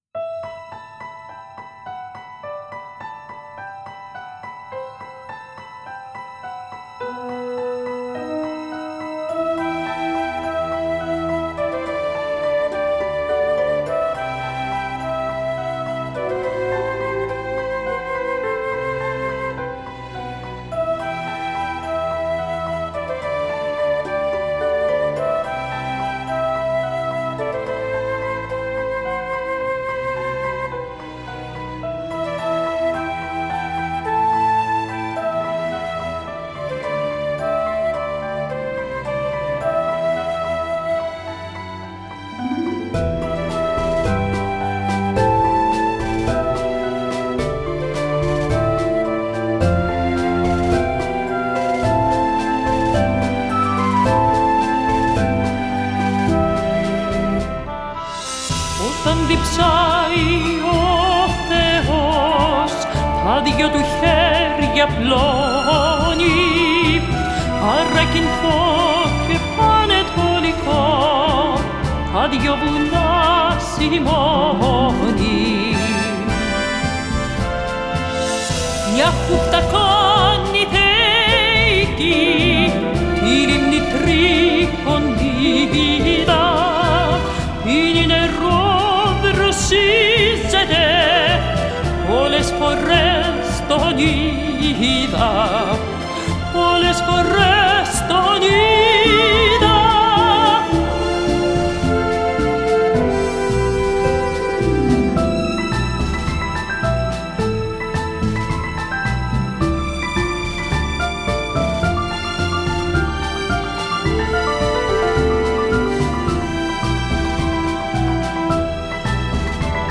Ερμηνεία στα τρία τραγούδια